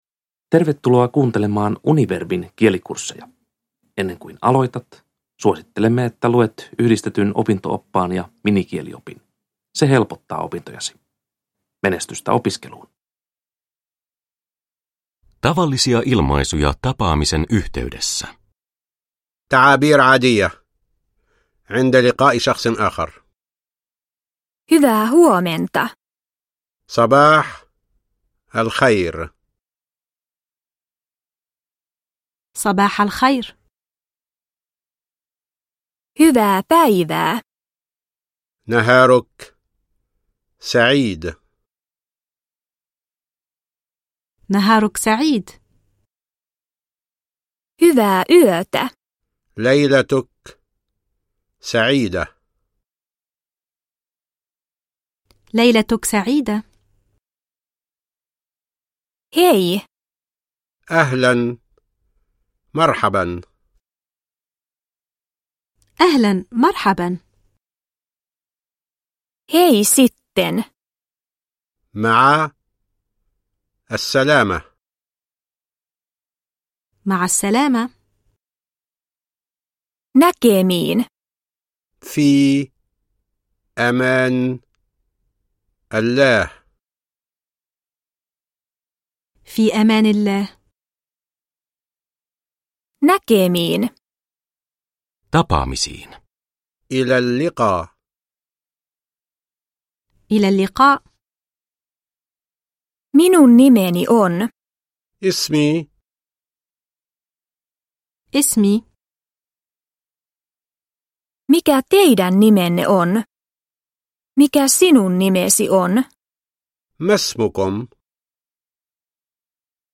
Arabian kielikurssi peruskurssi – Ljudbok – Laddas ner